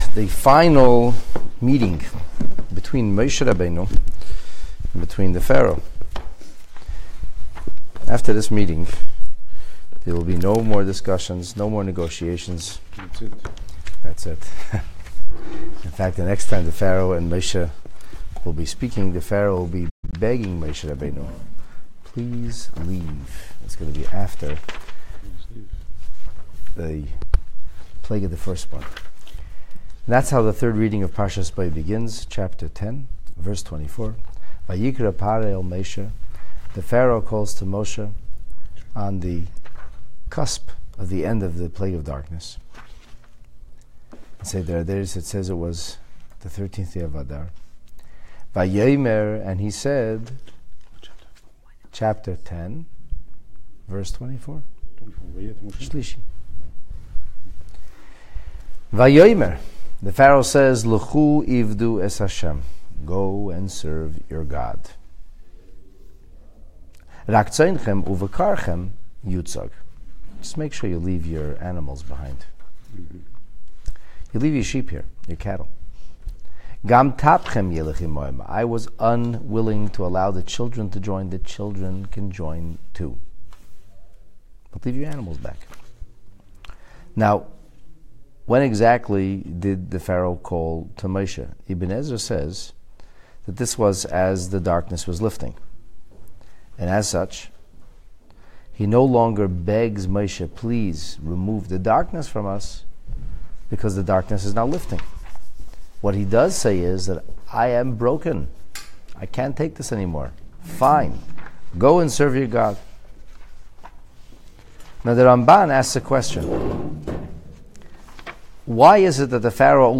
"Divine Demands" A powerful lesson on balancing HaShem’s expectations with human limitations. Parshat Bo, 3rd Portion (Exodus 10:24-11:3)